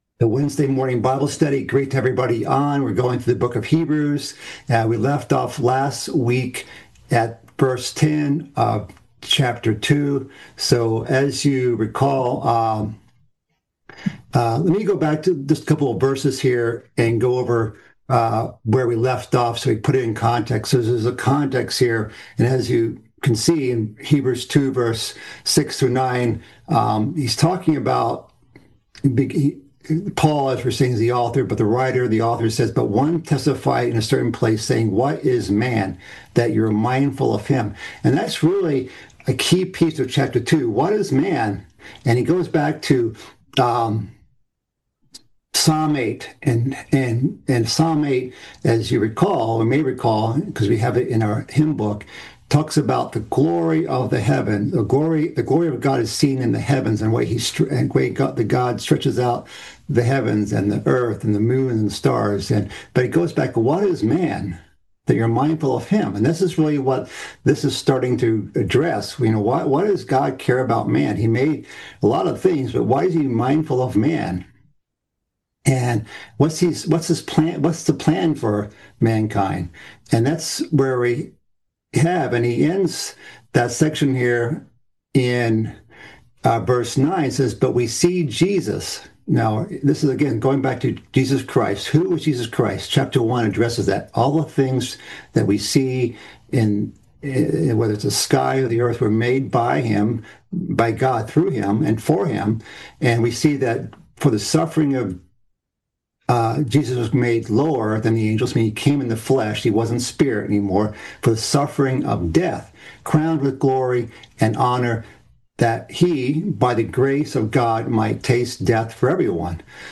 The seventh part in a series of mid-week Bible studies, covering the book of Hebrews. This session continues the second chapter of Hebrews.
Given in Northwest Indiana